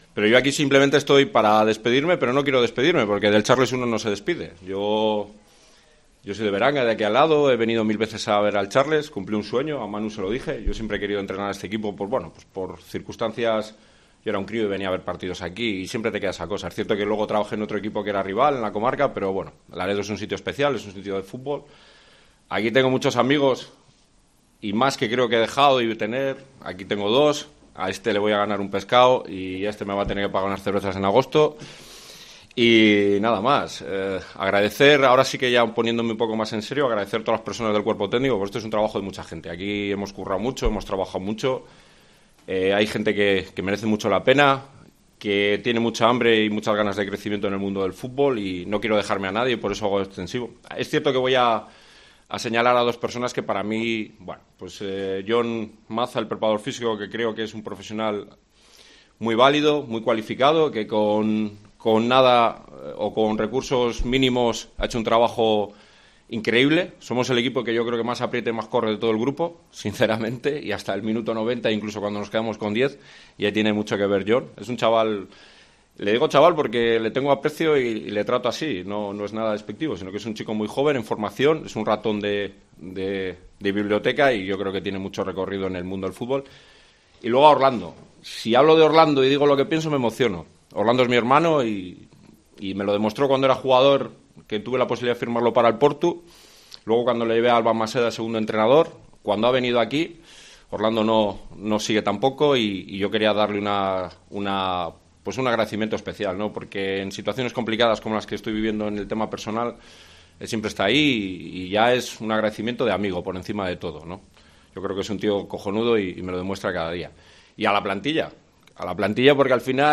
Lo intentaron, y tras una larga comparecencia de unos 45 minutos, simplemente se deduce que la primera plantilla necesita "un giro de timón", según el presidente.